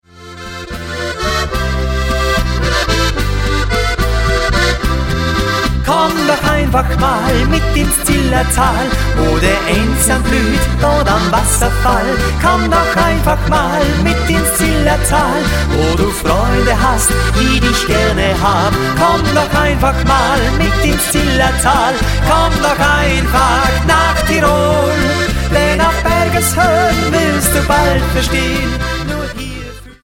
Genre: Volkst�mliche Musik Herkunft